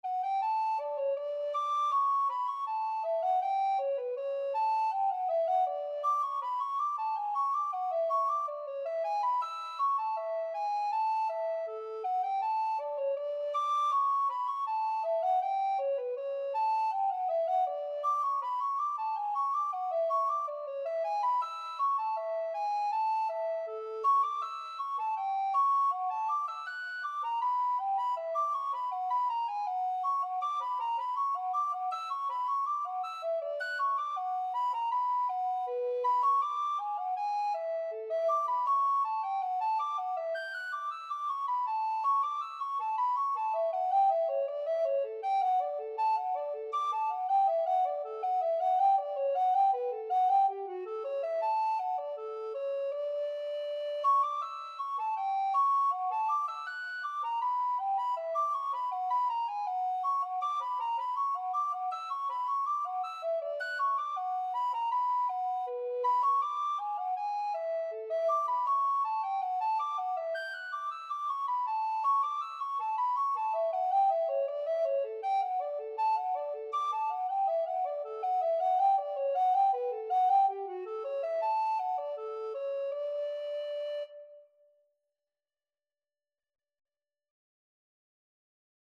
Classical Bach, Johann Sebastian JS Bach - Bouree from Suite No.3 Alto (Treble) Recorder version
D major (Sounding Pitch) (View more D major Music for Alto Recorder )
Alto Recorder  (View more Intermediate Alto Recorder Music)
Classical (View more Classical Alto Recorder Music)